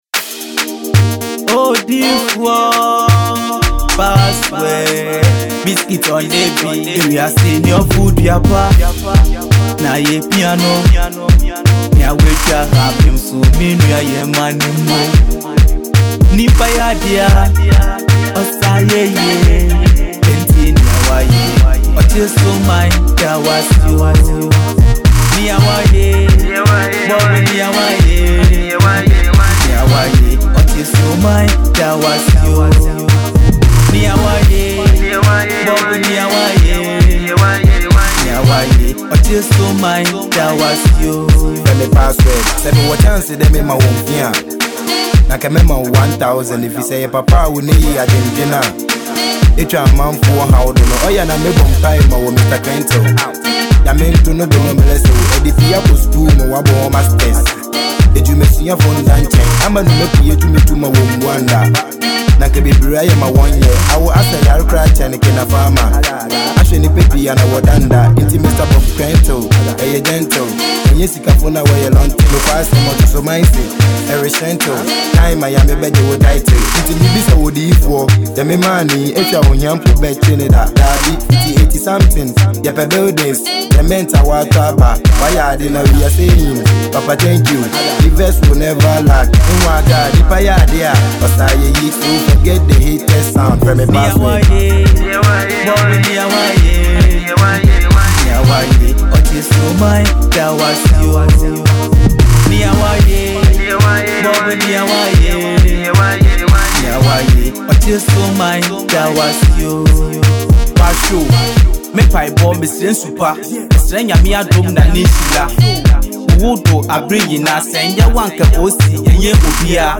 Sensational Hiphop/Hiplife Rapper